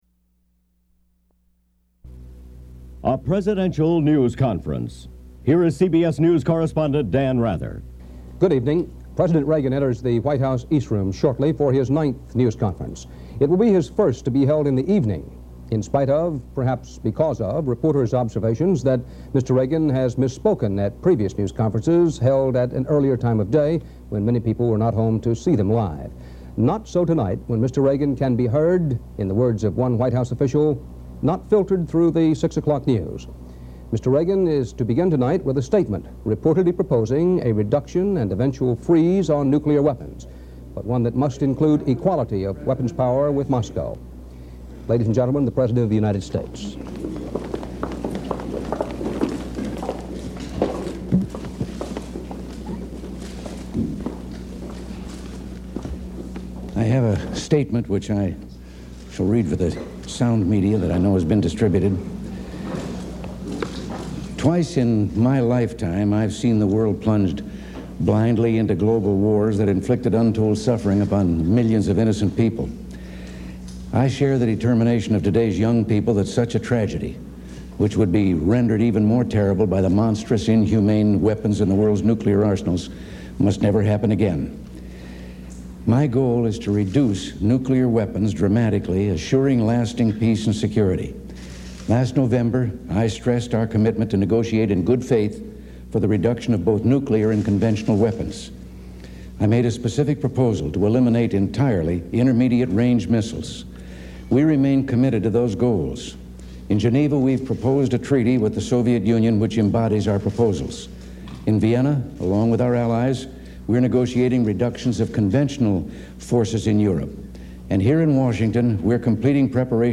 U.S. President Ronald Reagan gives his ninth news conference